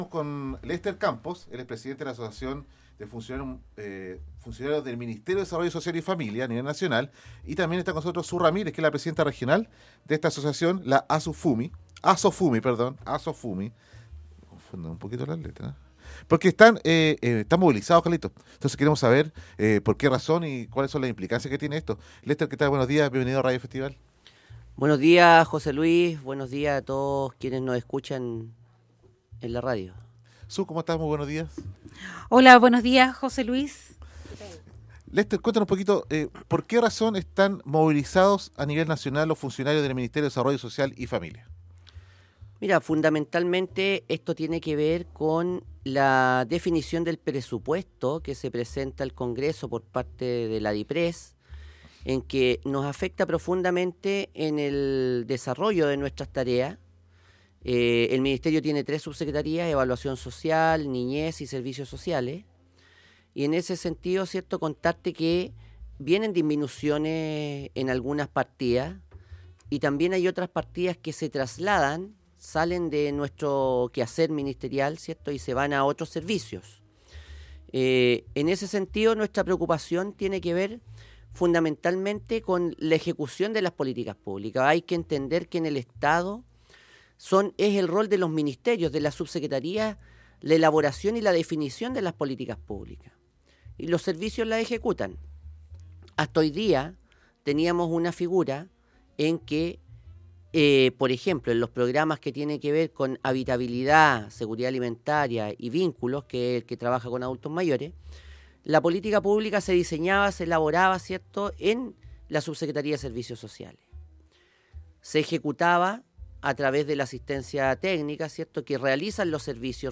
En estudios